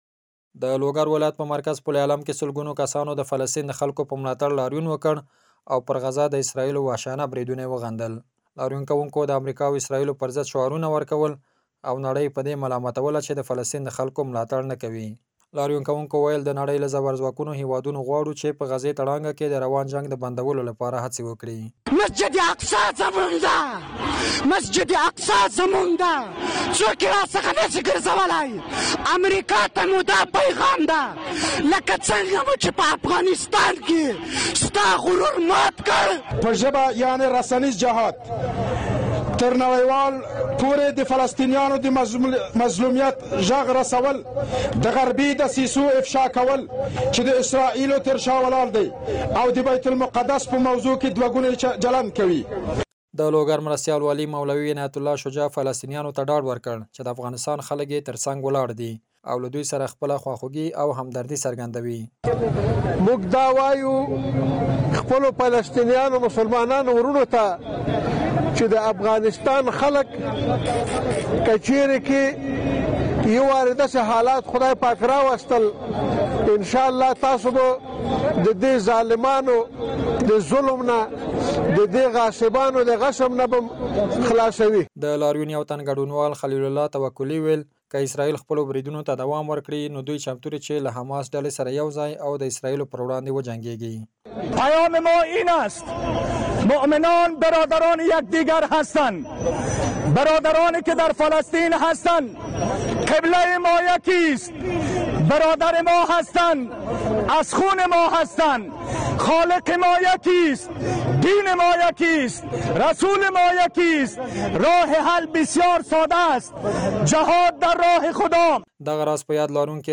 ایکنا-(کابل) د افغانستان د لوګر ولایت په مرکز پل علم کې سلګونو کسانو د فلسطین د خلکو په ملاتړ کې ستر لاريون وکړ او  د شعارونو په ورکولو سره ئې پر غزه د اسرائیلو وحشيانه بریدونه  وغندل.